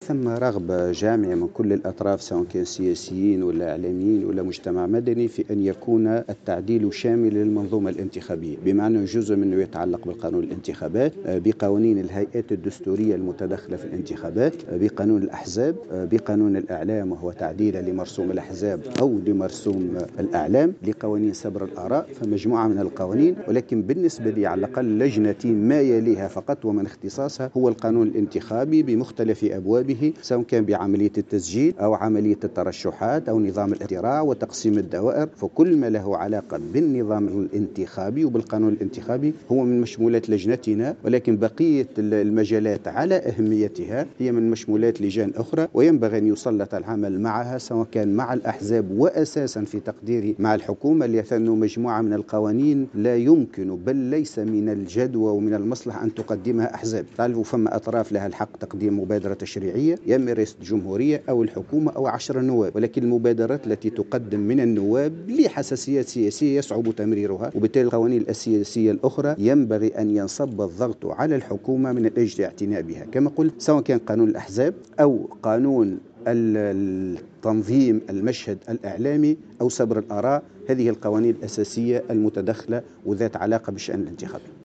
قال رئيس لجنة النظام الداخلي والحصانة البرلمانية والقانون الانتخابي ناجي الجمل في تصريح لمراسلة الجوهرة اف ام" اليوم الخميس، إن هناك رغبة من كل الاطراف سواء السياسية أو الاعلامية أو مكونات المجتمع المدني في أن يكون الاصلاح شاملا للمنظومة الانتخابية .